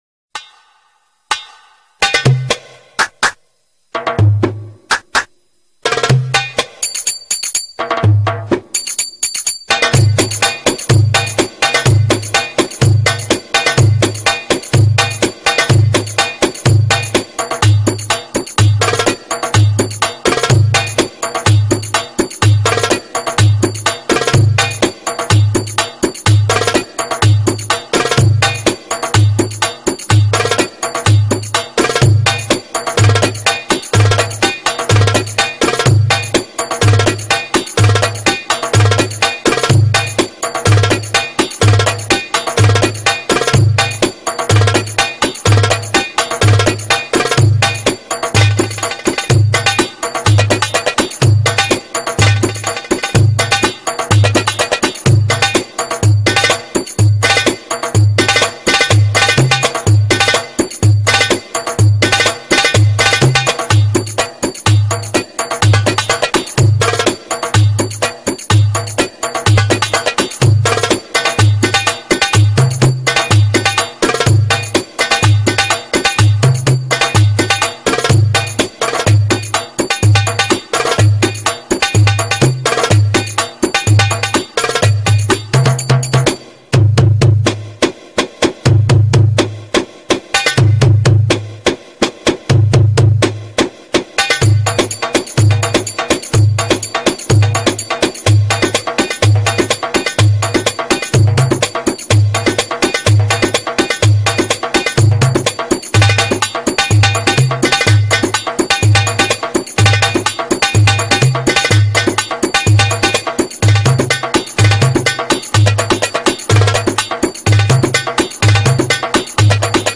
تِمپو
تِمپو یا داربوکا، یک ساز کوبه ای از خانوادهٔ طبل های جام گونه است که با هر دو دست نواخته می‌شود.
tempo.mp3